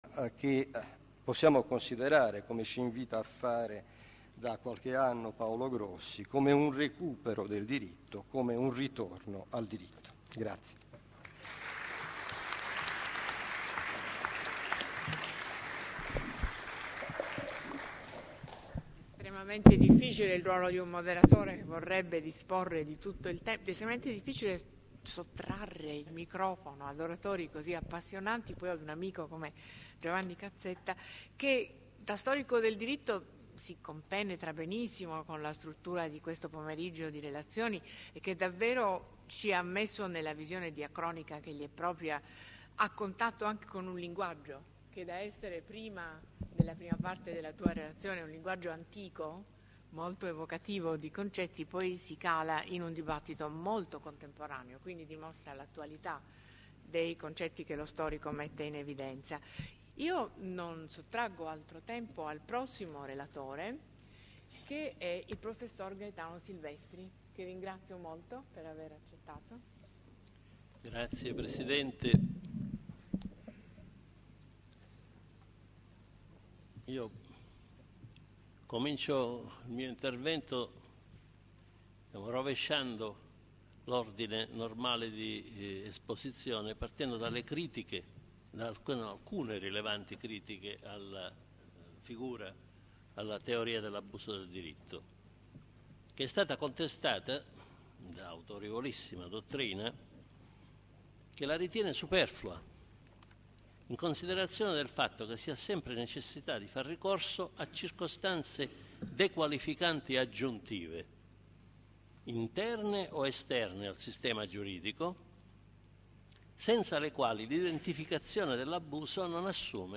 Convegno su "L’ABUSO DEL DIRITTO.